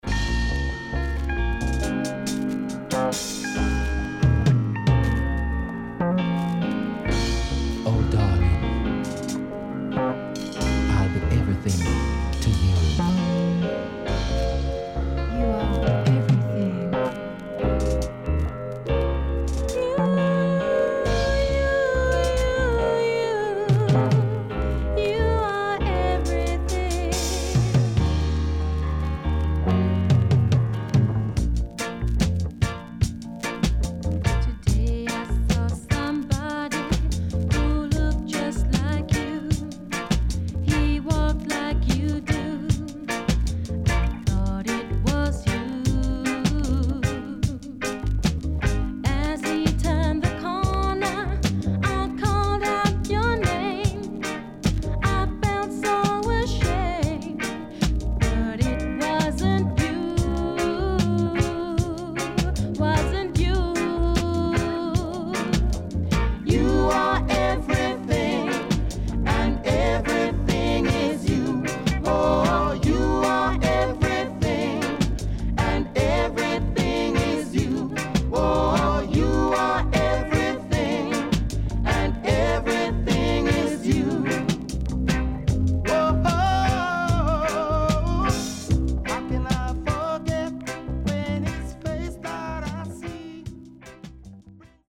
【12inch】-Color Vinyl
SIDE A:少しチリノイズ入りますが良好です。